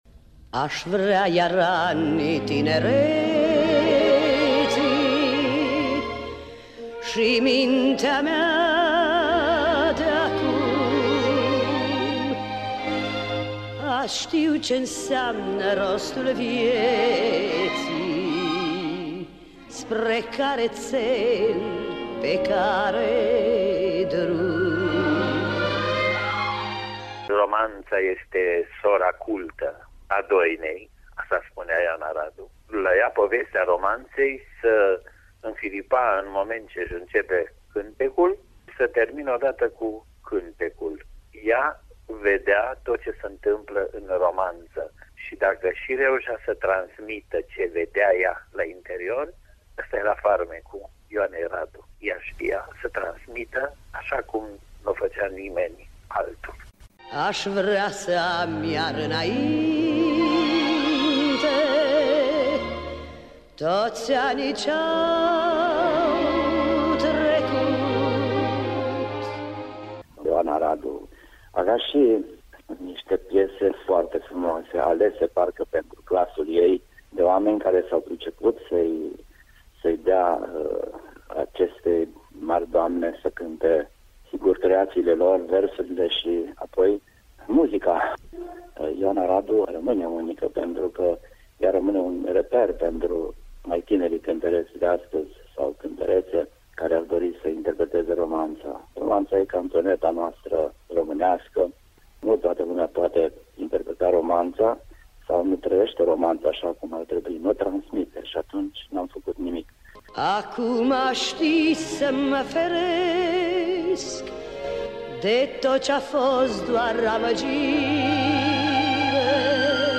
Fragmente din interviuri
Ioana-Radu-fragmente-interviu.mp3